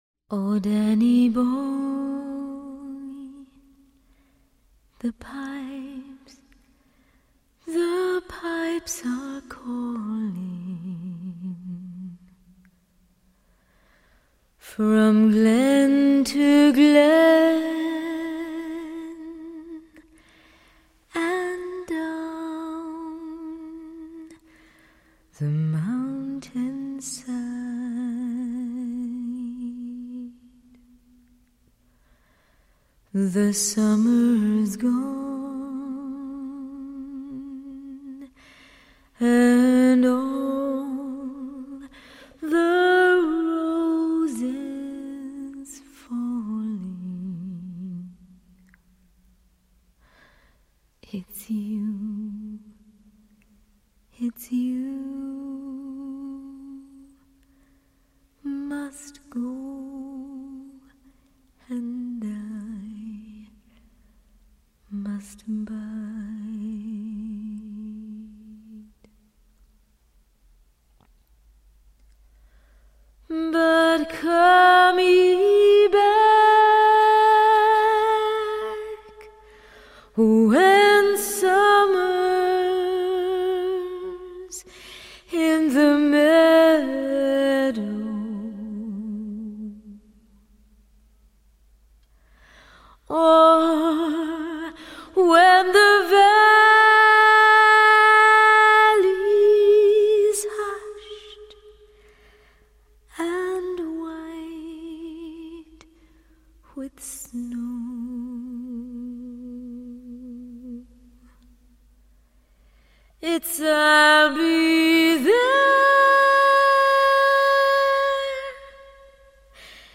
This is now an audiophile reference disc for female vocals.
vocals
tenor saxophone
piano
bass
drums.